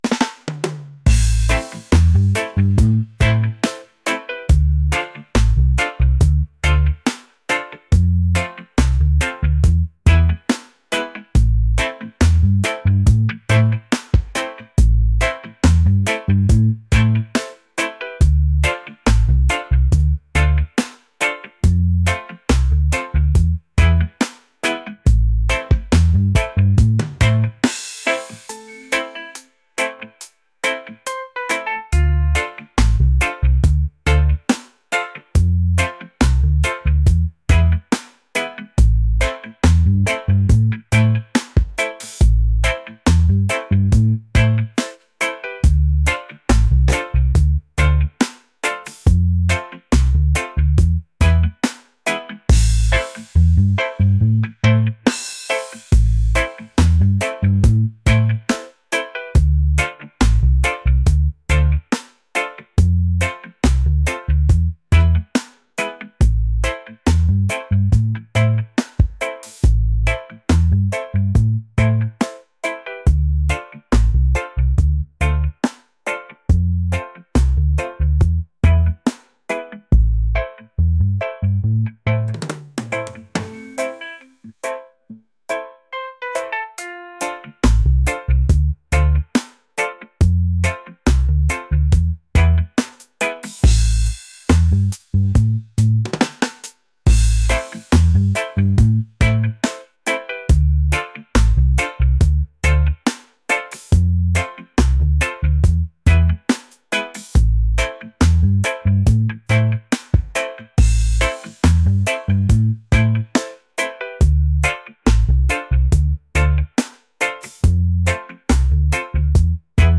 relaxed | laid-back | reggae